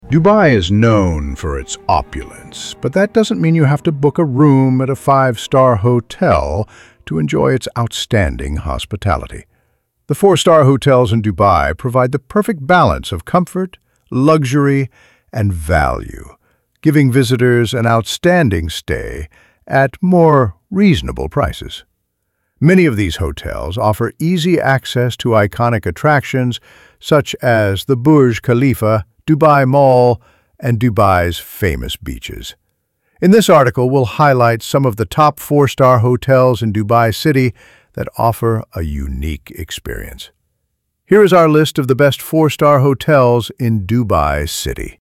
ElevenLabs_Text_to_Speech_audio-15.mp3